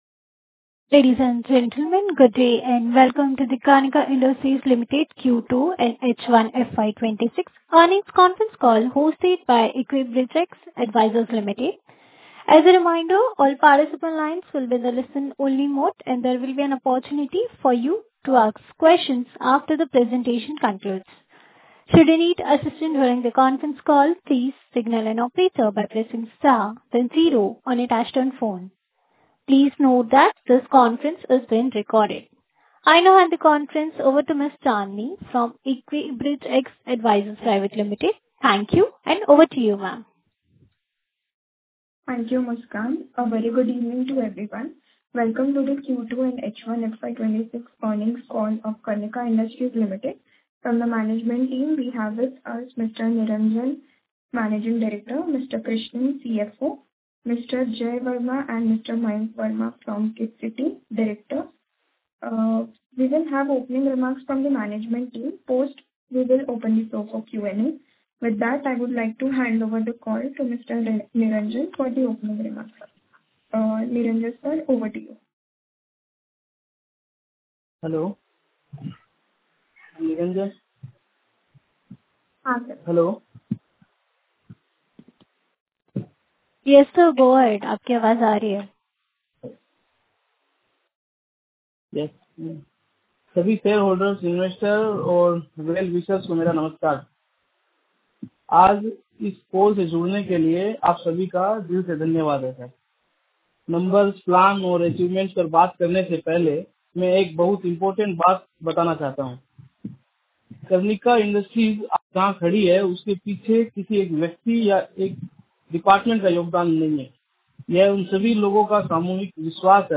Karnika Industries – Q2 & H1 FY25 Earnings Call Audio
Karnika-Industries-Q2-H1-FY25-Earnings-Call-Audio.mp3